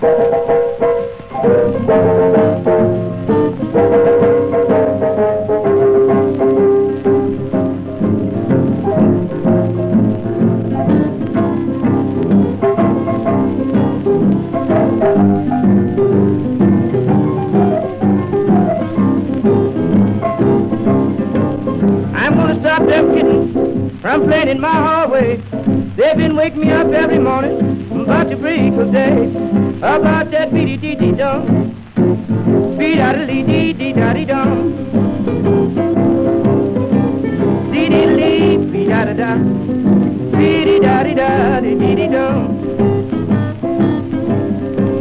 洒落っ気や都会的と形容されるブルースの音。
Blues　Netherlands　12inchレコード　33rpm　Stereo